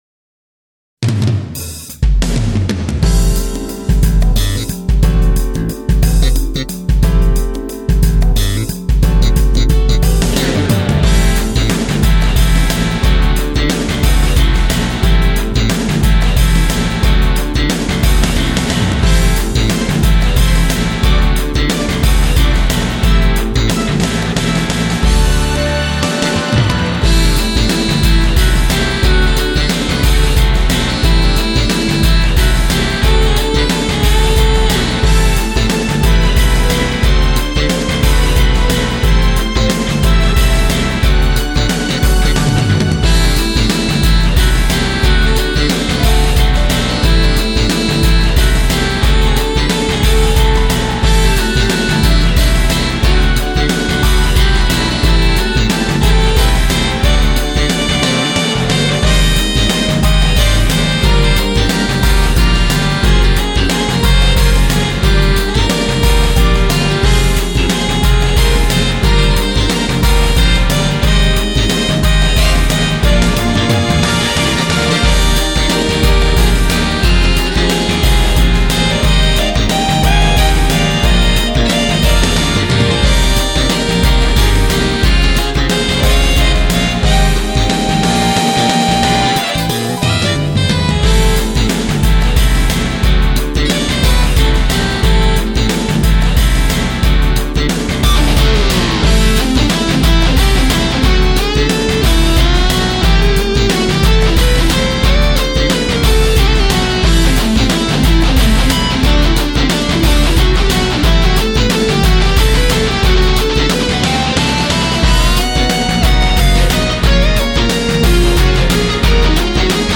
ファイルは「YAMAHA MU1000EX」「Roland SC-8850、INTEGRA-7」で制作したものを